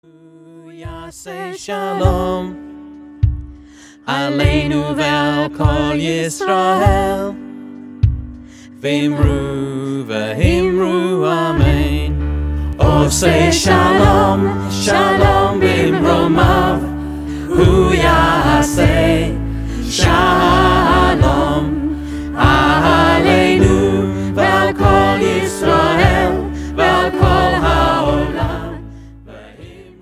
A rocking and whimsical collection of songs